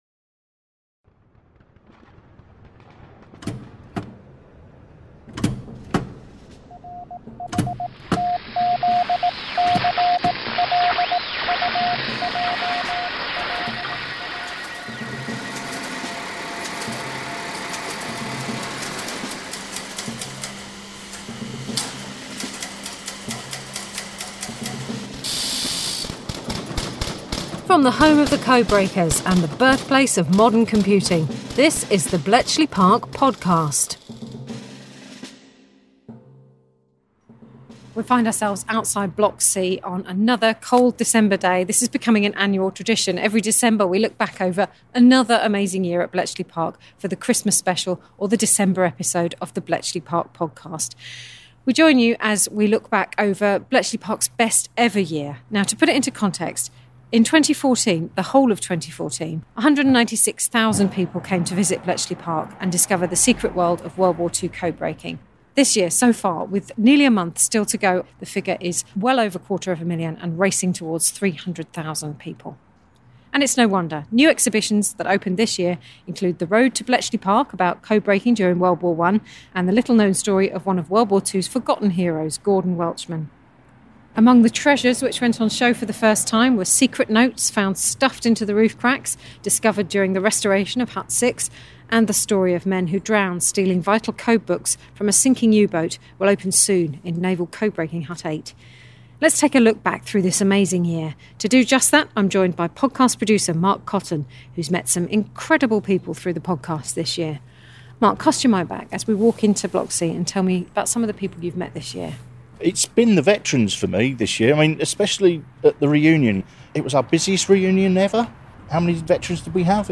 0:37.4 We find ourselves outside Block C on another cold December day.